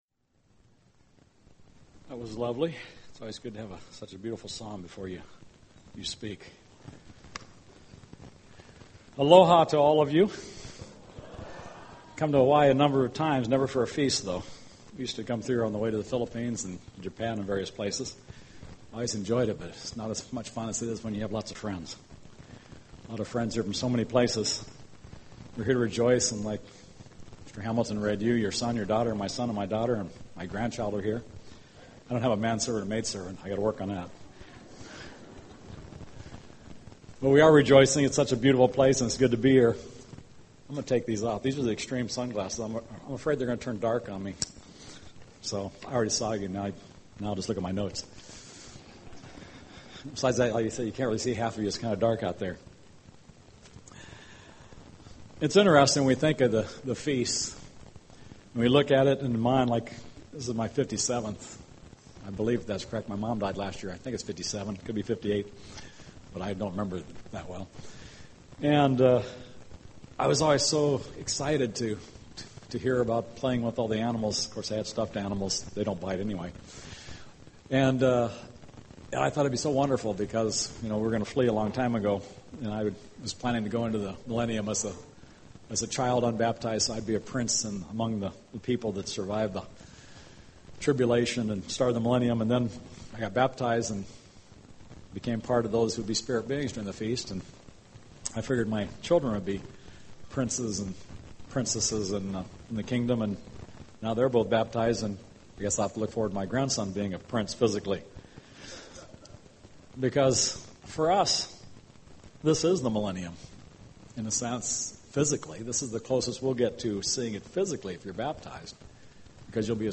This sermon was given at the Maui, Hawaii 2011 Feast site.